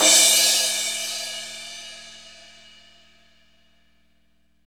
Index of /90_sSampleCDs/Northstar - Drumscapes Roland/CYM_Cymbals 1/CYM_F_S Cymbalsx